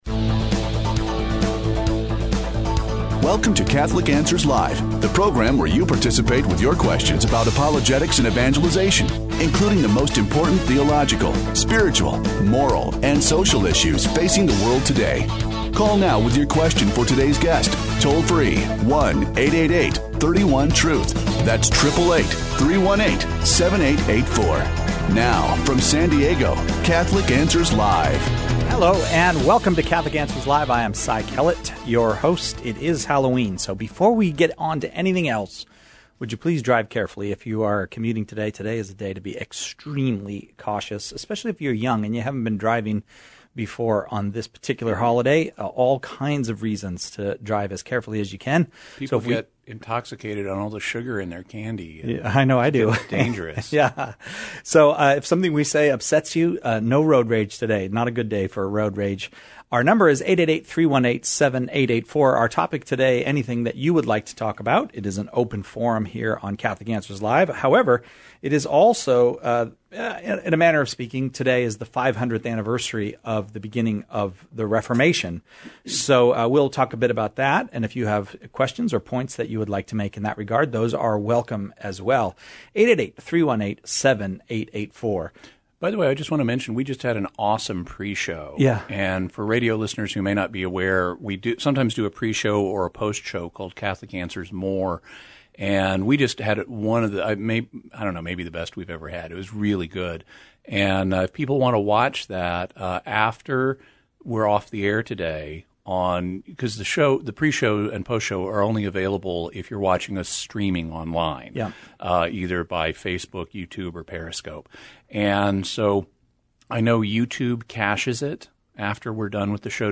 The callers choose the topics during Open Forum, with questions on every aspect of Catholic life and faith, the moral life, and even philosophical topics.